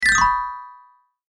チェレスタグリッサンド4.mp3